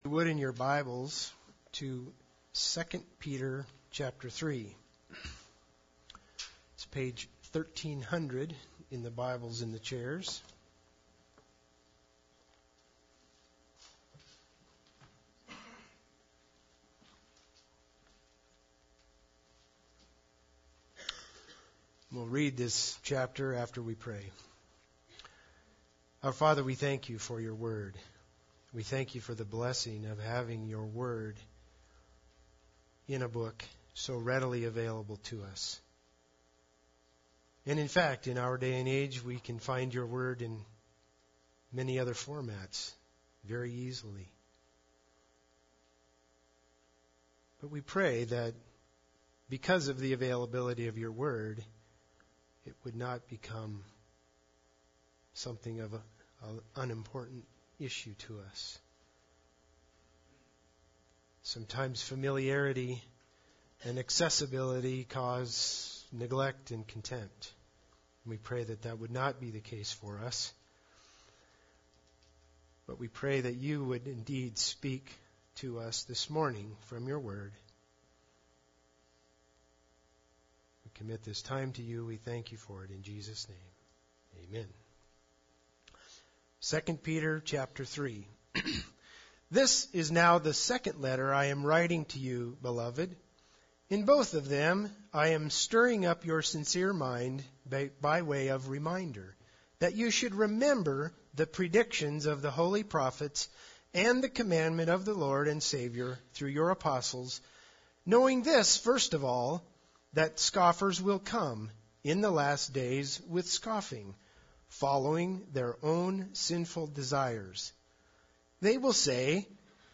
2 Peter 3 Service Type: Sunday Service Bible Text